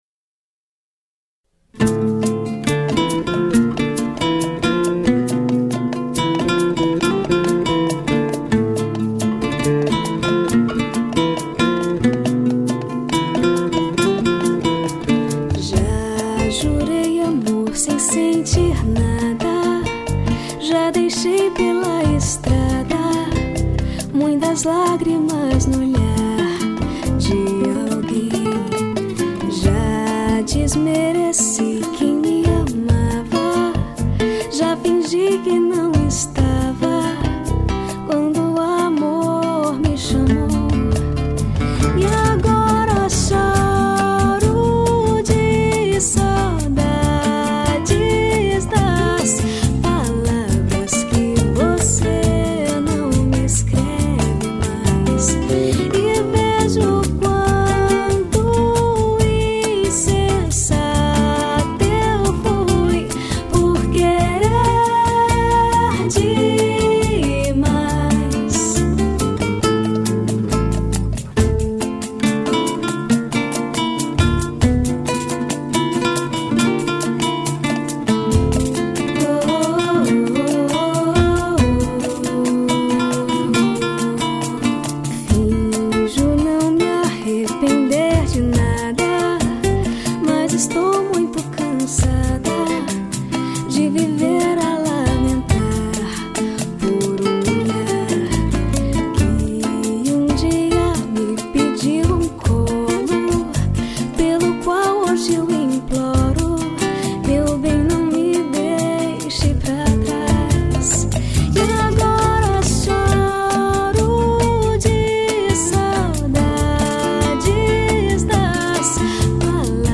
2817   03:30:00   Faixa: 7    Mpb